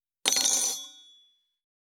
251,食器にスプーンを置く,ガラスがこすれあう擦れ合う音,カトラリーの音,食器の音,会食の音,食事の音,カチャン,コトン,効果音,環境音,BGM,カタン,チン,コテン,コン,カチャ,チリ,コト,
コップ